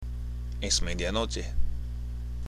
（エス　メディアノチャ）